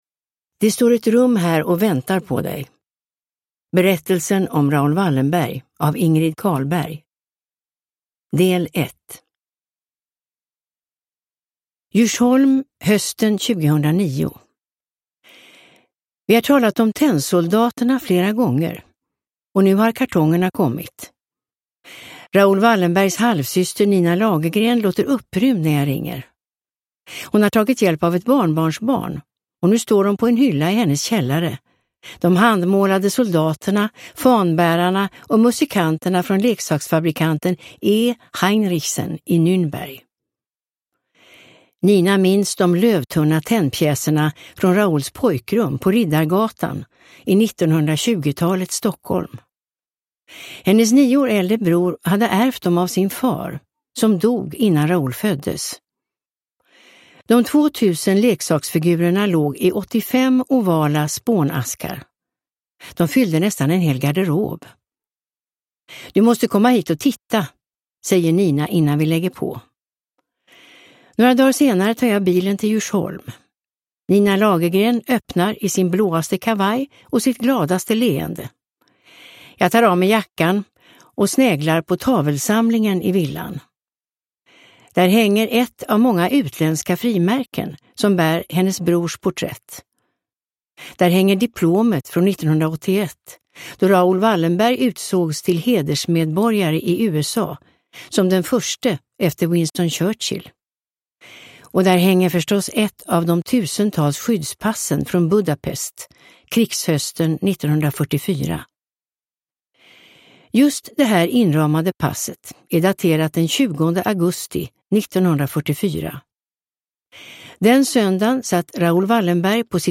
Uppläsare: Irene Lindh